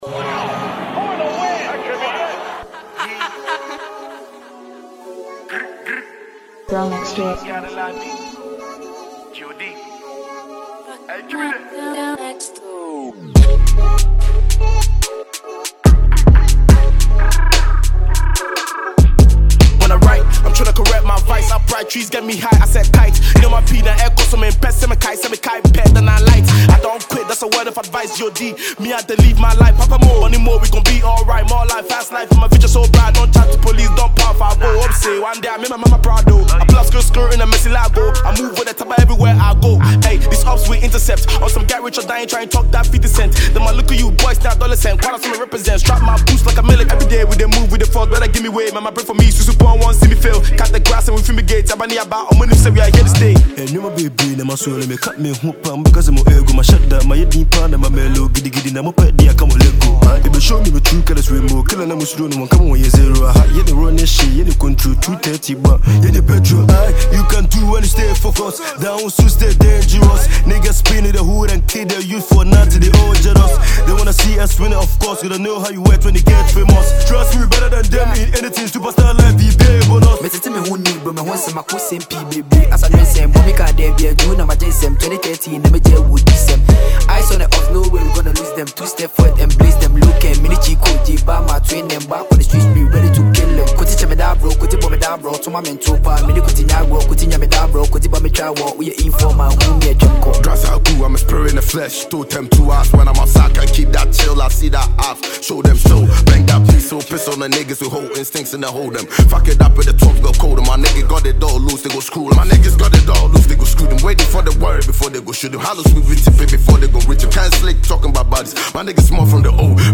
Asakaa rapper